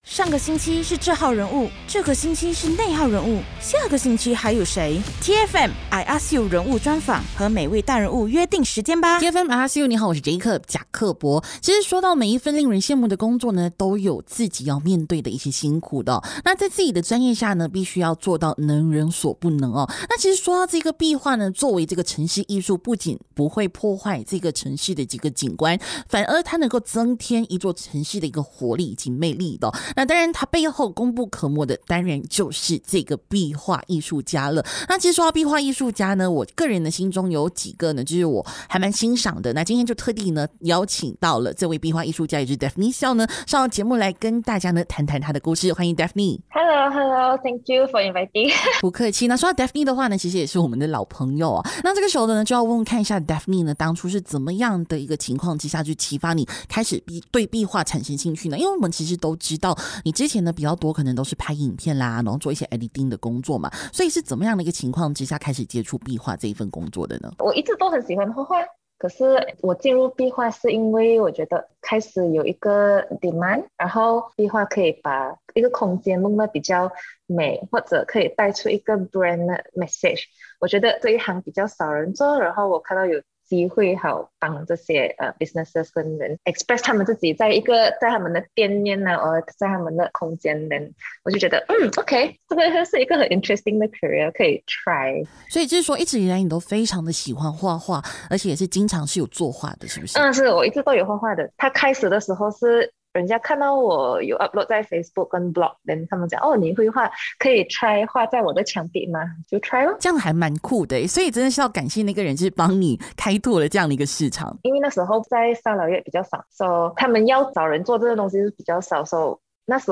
人物专访